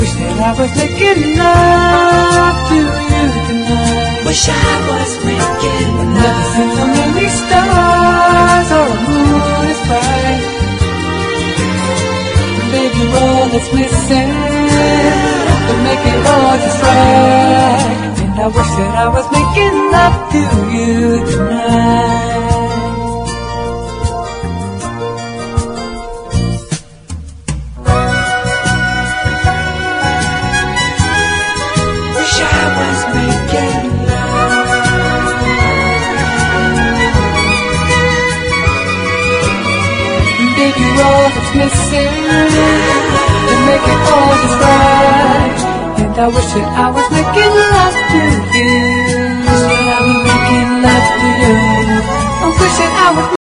ROCK / S.S.W./A.O.R. / BLUE EYED SOUL / YACHT ROCK
ロマンティックにこみあがる甘口A.O.R.2ND！
ソフトに包み込むミディアム・トラック